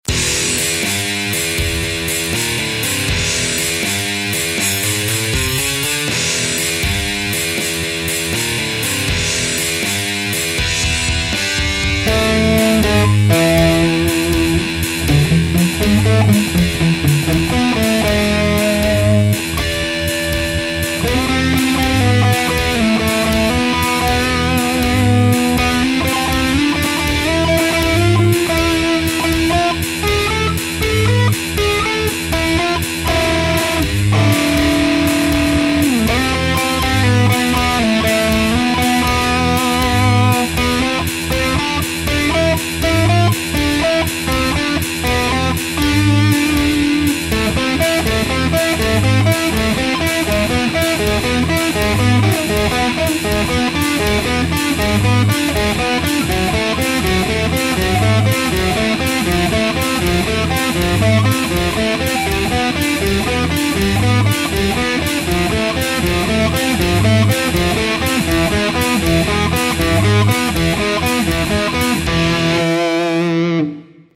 Записал импровизацию :gg: Бас лень было прописывать, правда - поздно уже (